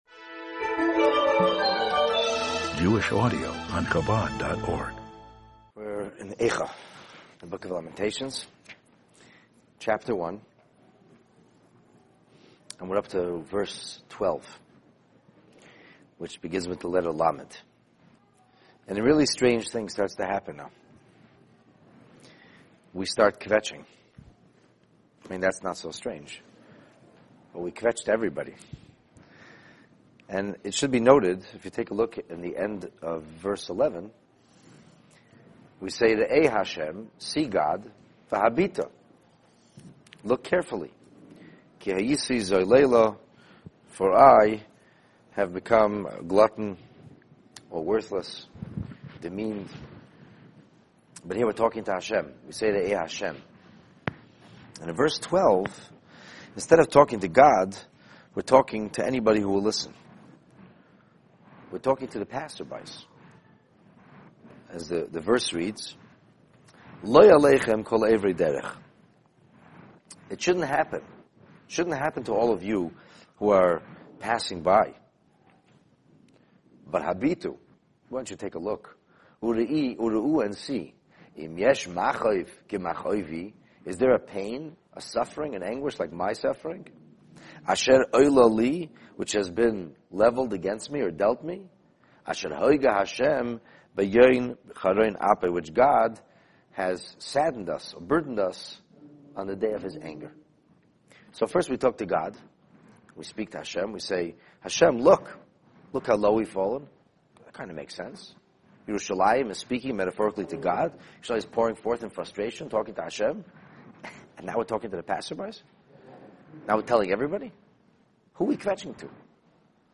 In this class we listen to Jerusalem pour her heart out to proverbial passersby in emotional distress, bitterly lamenting her misfortune and destruction. The in-depth analyses of this verse reveals how this poetic prophecy provide insight, encouragement and inspiration to help us deal with our own painful realities, as well as appreciate the highs, lows and unique travails of our collective Jewish experience!